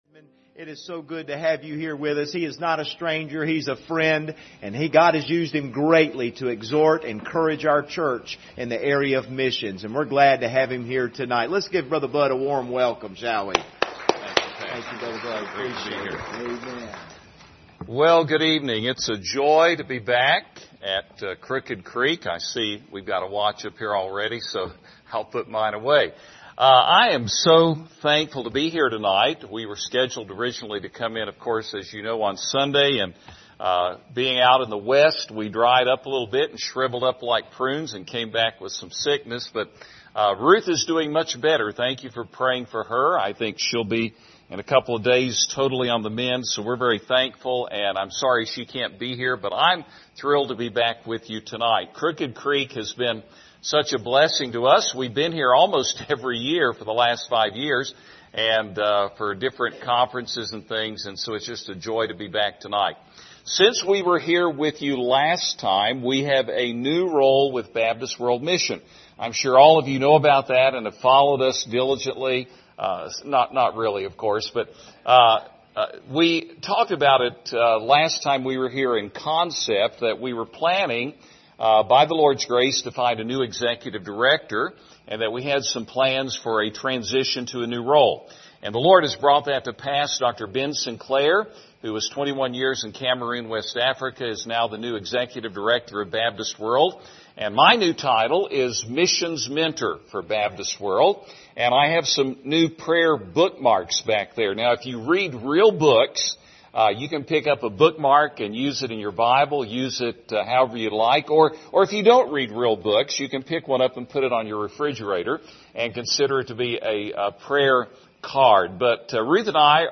Series: 2023 Missions Conference Passage: Acts 5 Service Type: Special Service